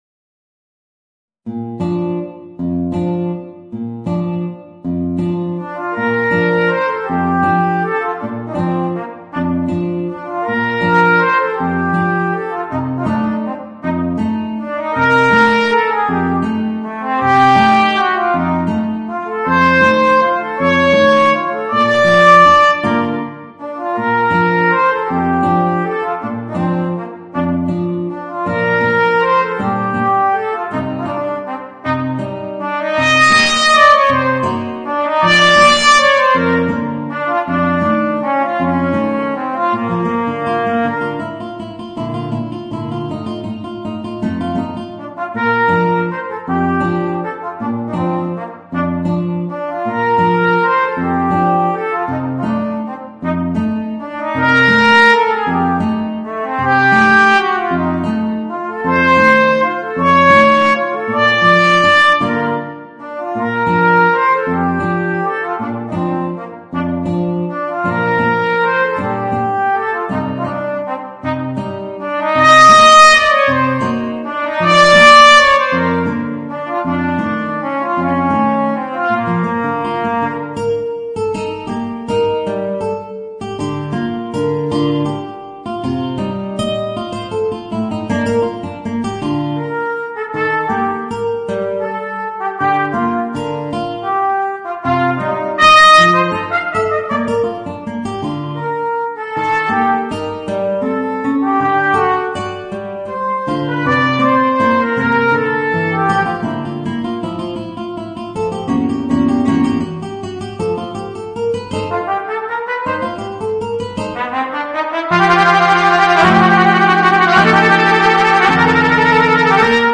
Voicing: Guitar and Trumpet